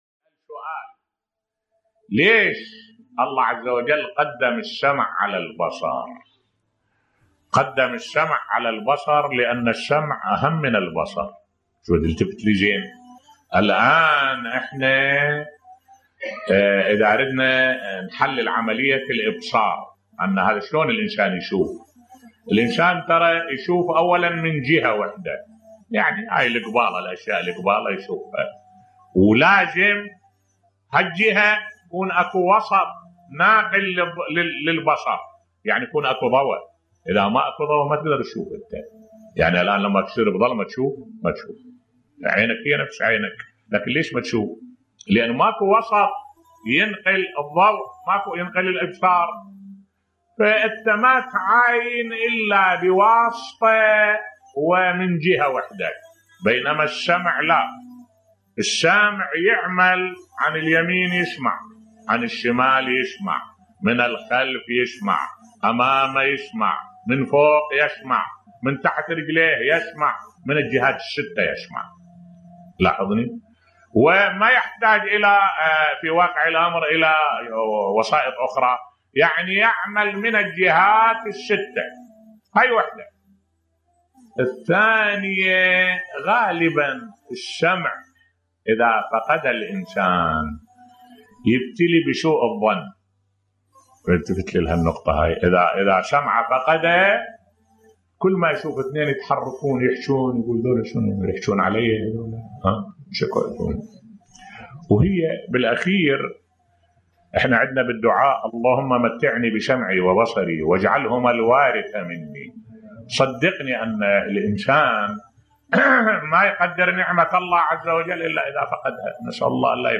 ملف صوتی لماذا يقدم السمع على البصر بصوت الشيخ الدكتور أحمد الوائلي